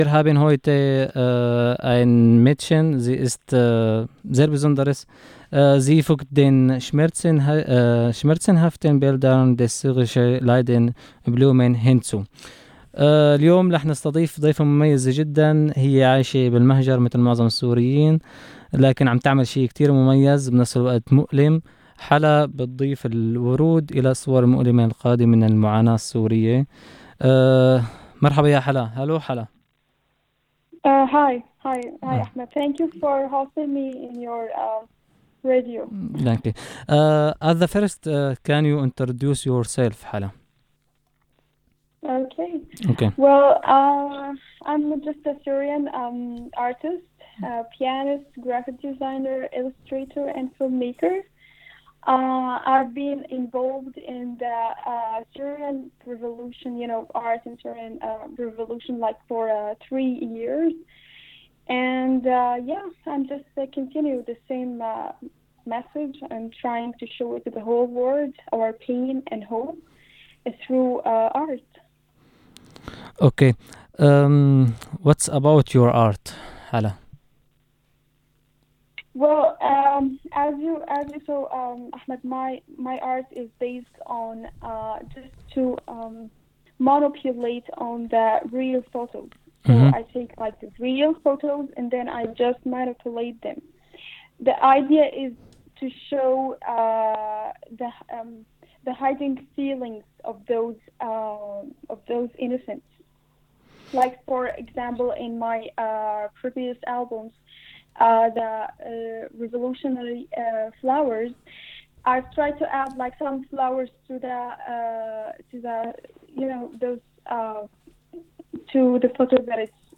Das Interview ist auf Englisch